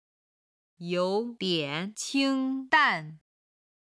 yǒu diǎn qīng dàn
ヨウ　ディェン　チン　ダン